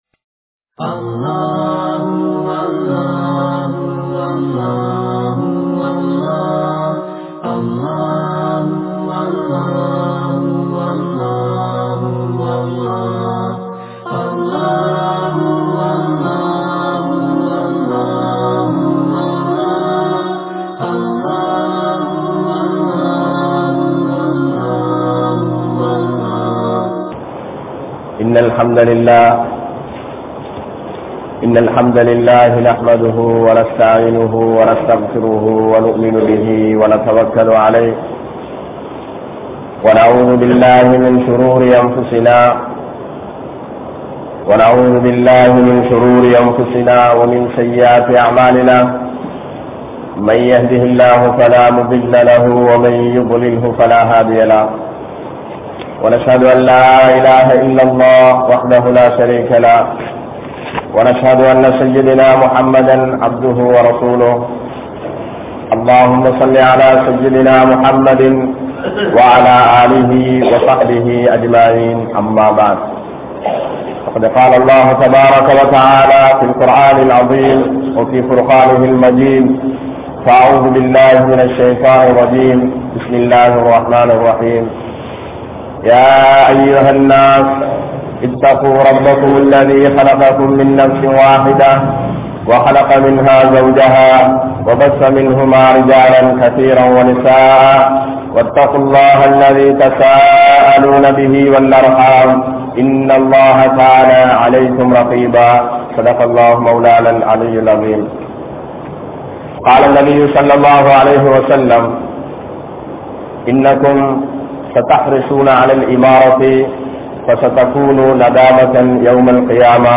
Masjidhin Niruvaaha Safaikku Yaar Thauthi? (மஸ்ஜிதின் நிருவாக சபைக்கு யார் தகுதி?) | Audio Bayans | All Ceylon Muslim Youth Community | Addalaichenai
Gallella Jumua Masjidh